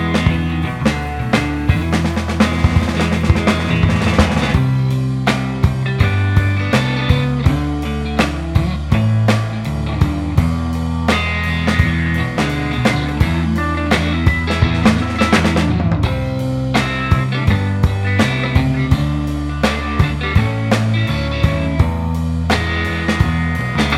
Minus Main Guitar Blues 3:39 Buy £1.50